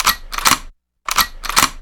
GunCock01.wav